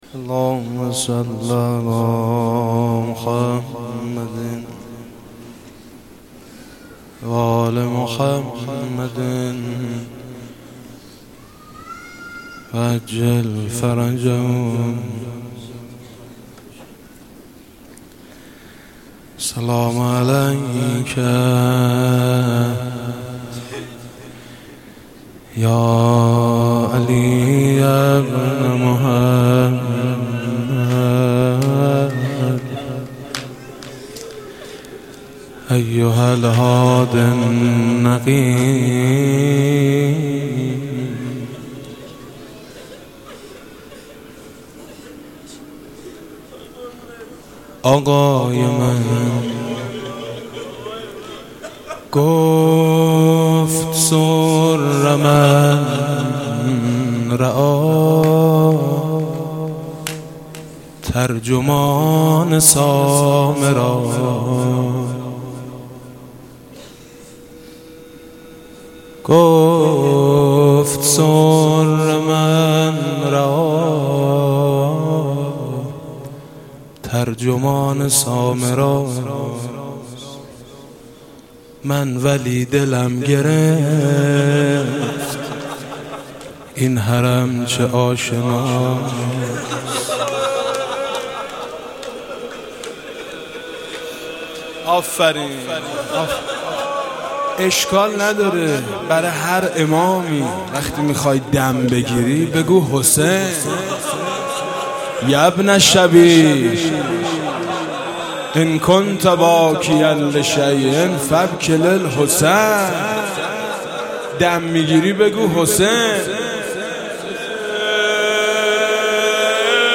ویژه شهادت امام حسن عسکری (مدح)